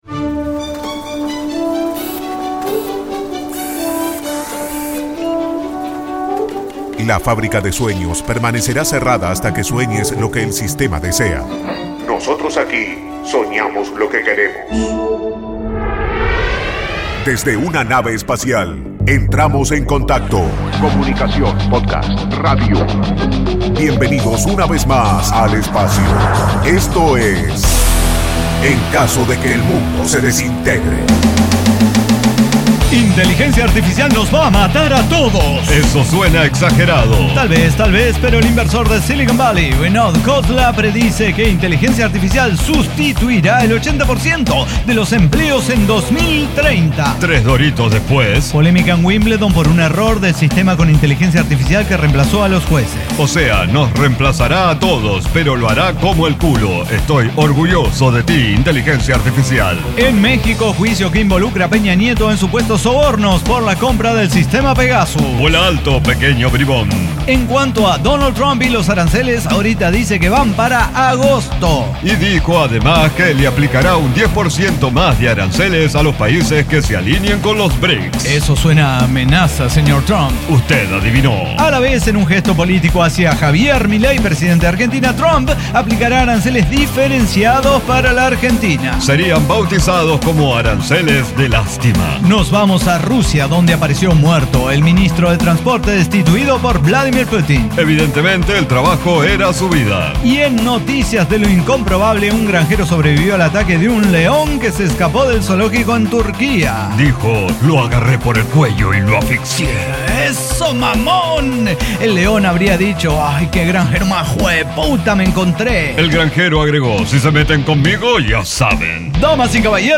Noticias de actualidad internacional y Comedia - Cuando una tonta lesión te puede arruinar una carrera brillante
Diseño, guionado, música, edición y voces son de nuestra completa intervención humana.